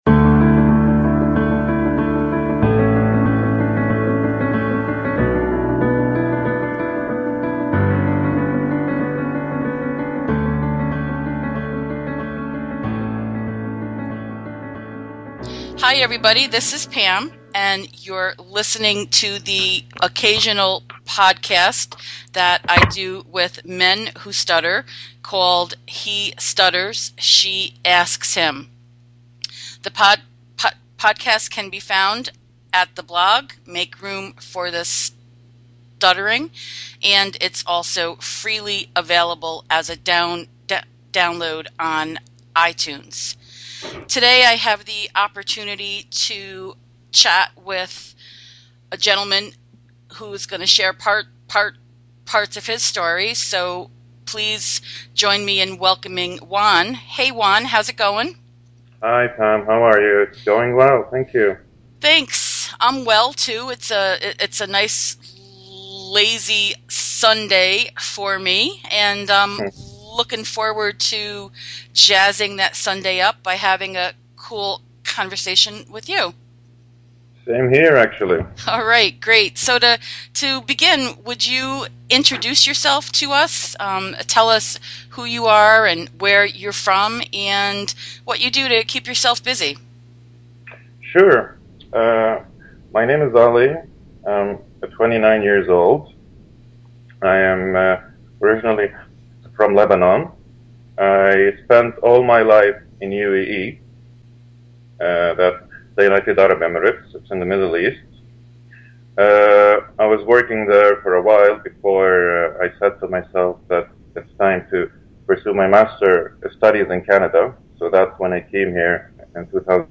Episode 13 of this series of conversations with men who stutter